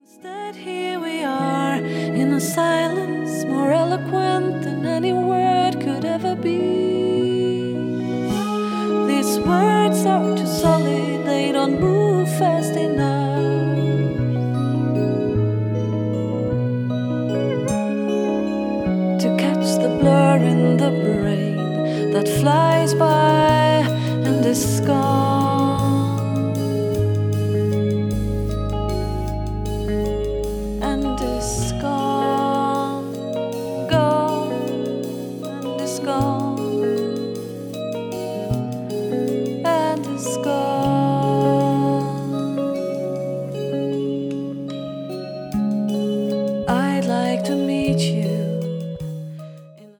voice and live electronics
acoustic bass, electric bass
piano, Fender Rhodes, keyboards
drums and percussions